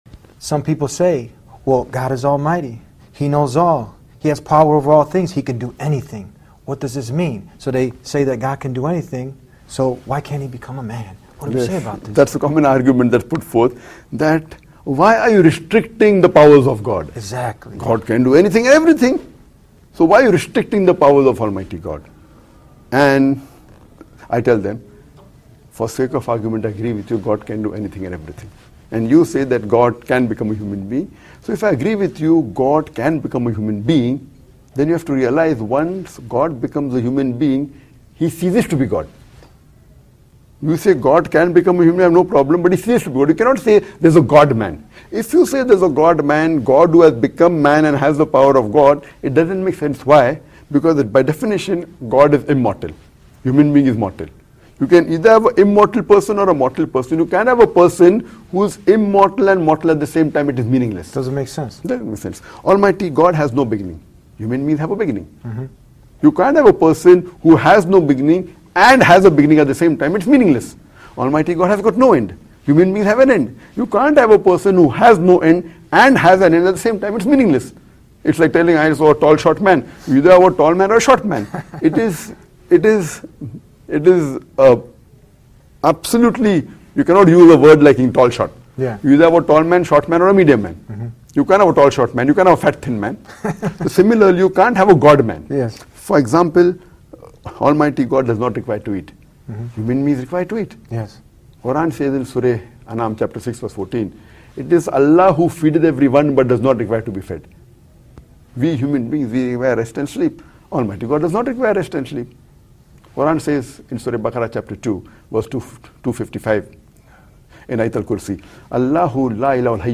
Most people believe that God can do anything. Dr. Zakir Naik explains in this clip: